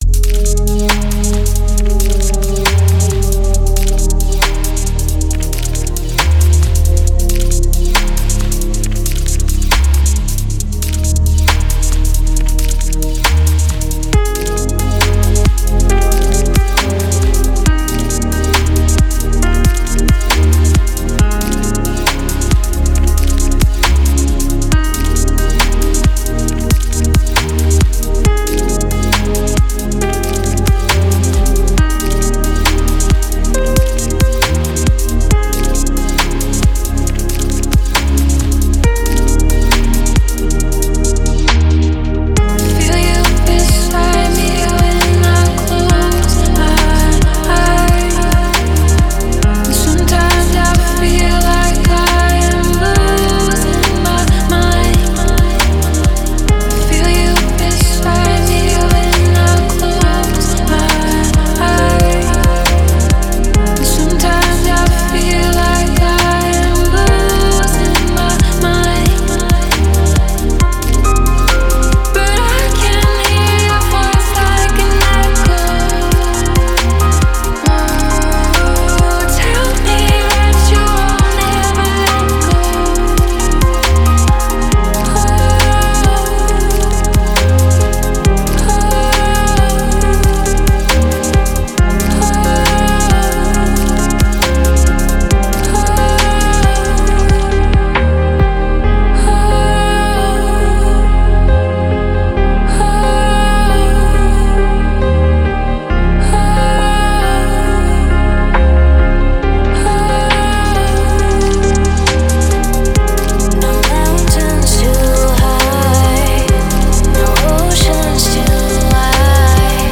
Музыка для успокоения души
Спокойная музыка
спокойные треки